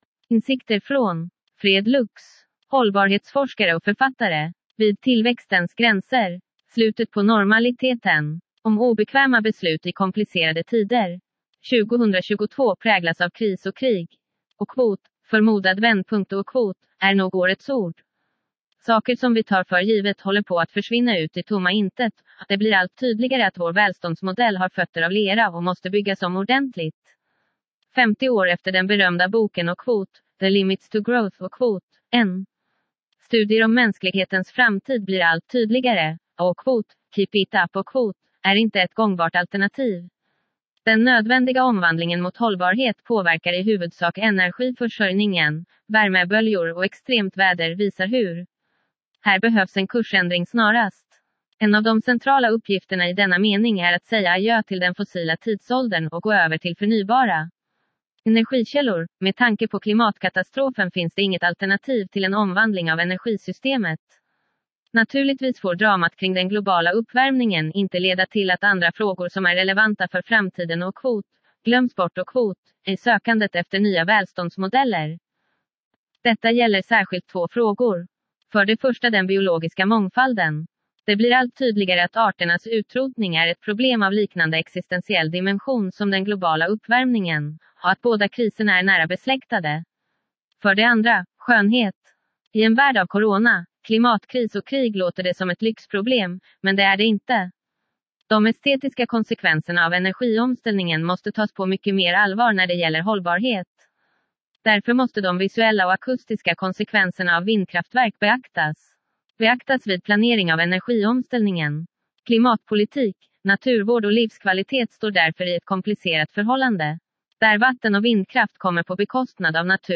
Einsichten Deutsch Weiblich Schwedisch Weiblich English Weiblich Italian Weiblich 2 views Share Download How was the audio ?